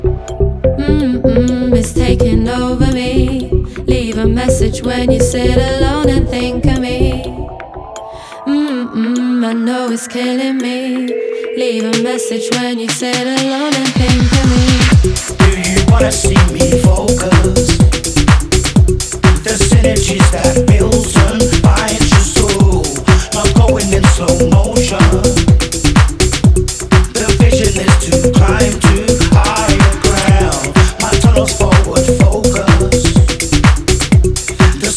Жанр: Танцевальные / Техно